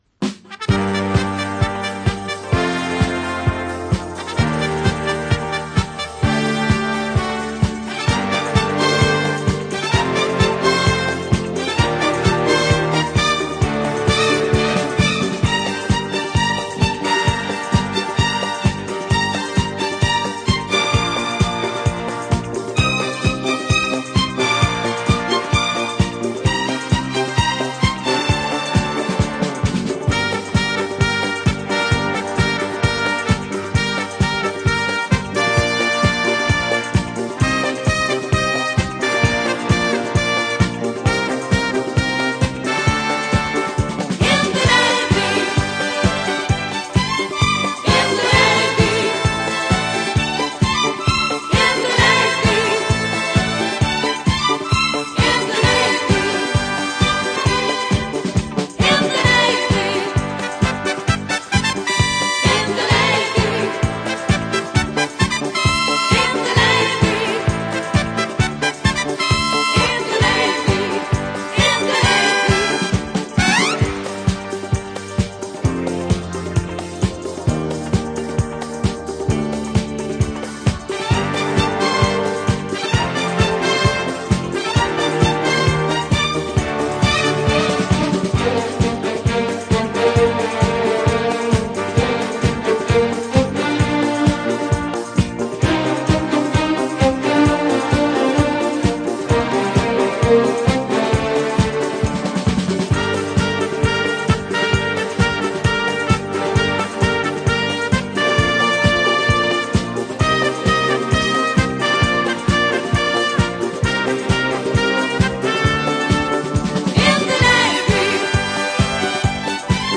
Жанр: Easy Listening